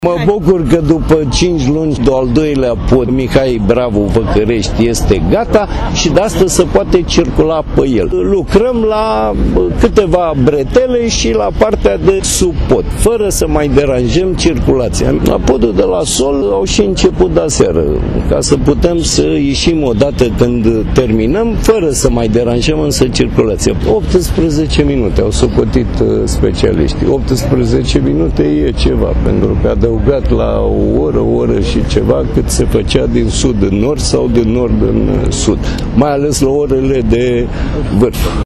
Oprescu-inaugurare-Mihai-Bravu.mp3